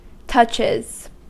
Ääntäminen
Ääntäminen US Haettu sana löytyi näillä lähdekielillä: englanti Käännöksiä ei löytynyt valitulle kohdekielelle. Touches on sanan touch monikko.